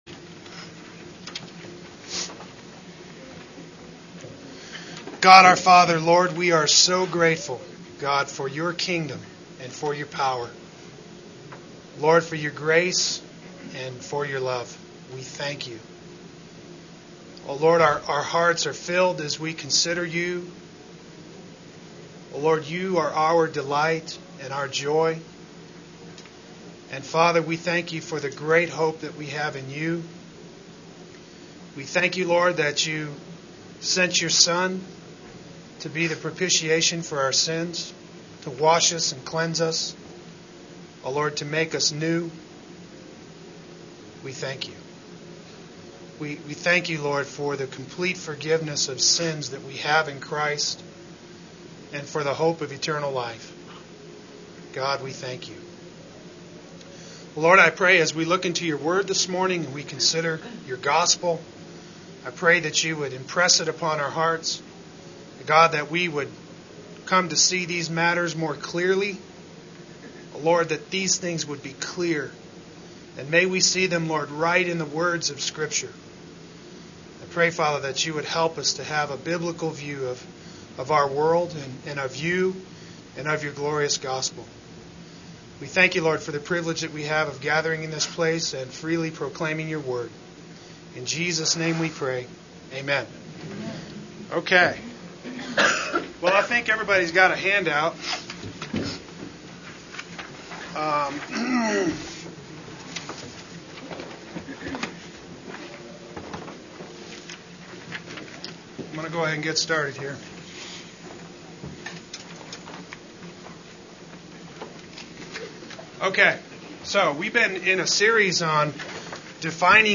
Lesson 4 Defining the Gospel Adult Sunday School